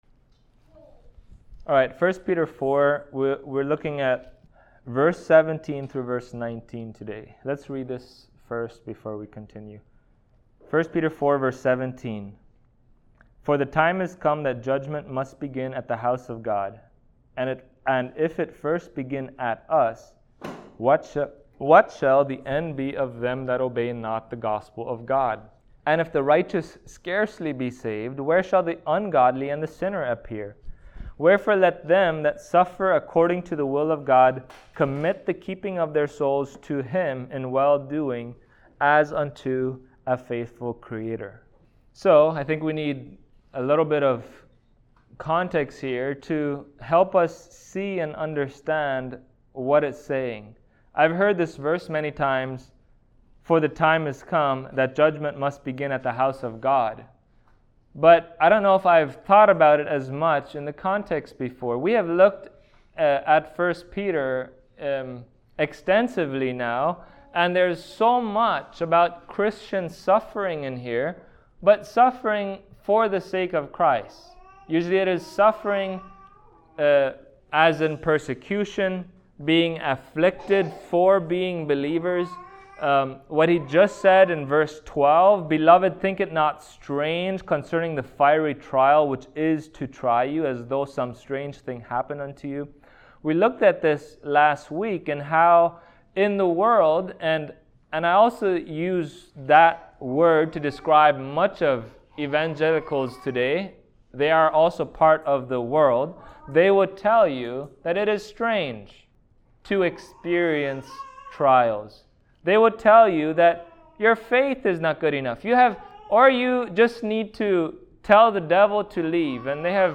Passage: 1 Peter 4:17-19 Service Type: Sunday Morning